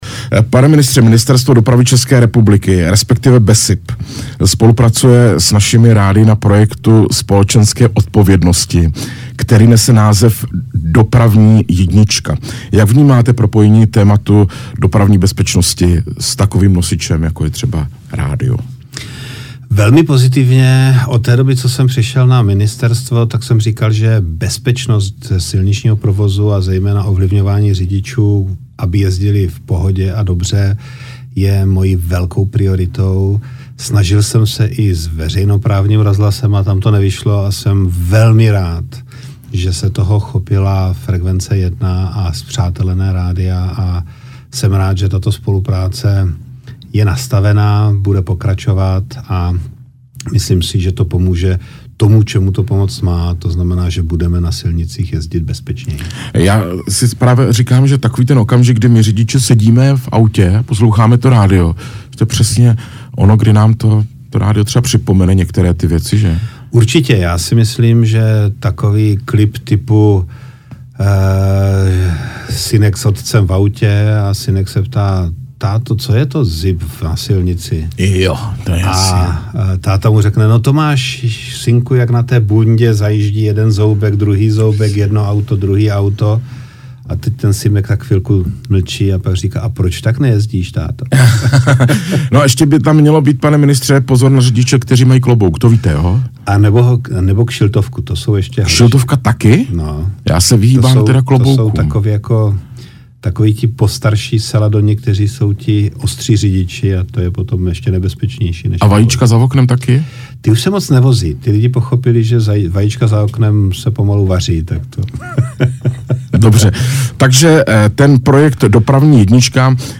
Hostem nedělního Pressklubu byl ministr dopravy Dan Ťok. Jedním z témat rozhovoru byla spolupráce BESIPu s rádii v rámci projektu Dopravní jednička.